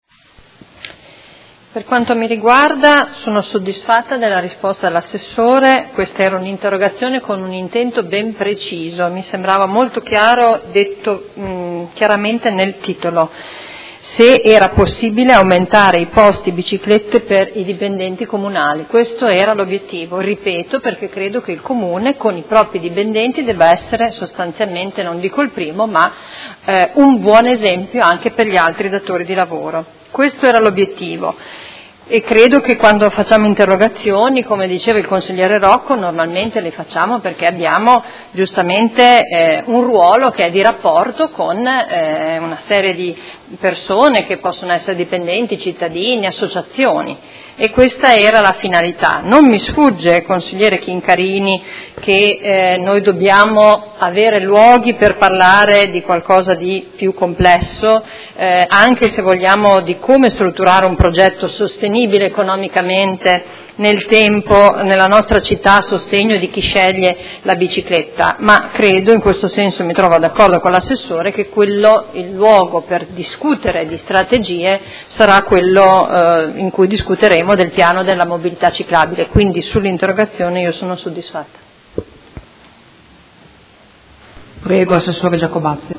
Simona Arletti — Sito Audio Consiglio Comunale
Dibattito su interrogazione della Consigliera Arletti (PD) avente per oggetto: Positiva iniziativa BIKETOWORK – Sono realizzabili dei posti protetti per biciclette dei dipendenti comunali?